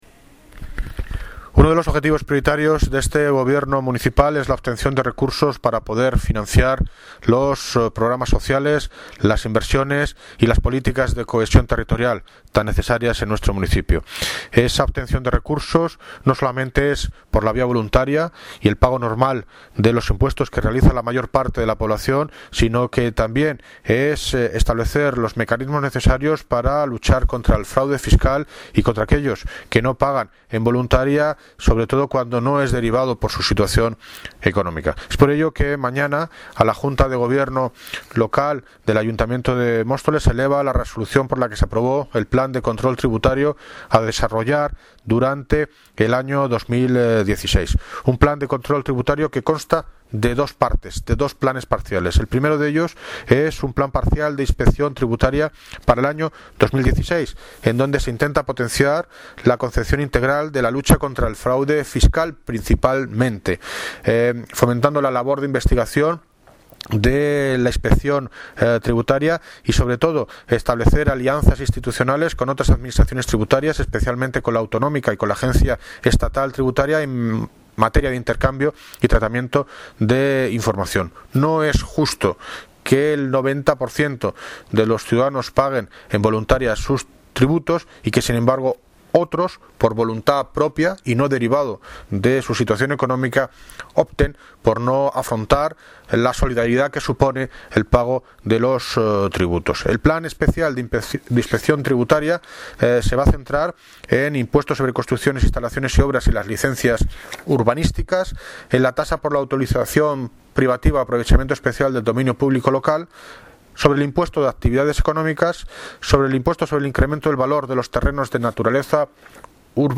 Audio - David Lucas (Alcalde de Móstoles) Sobre Lucha contra el Fraude Fiscal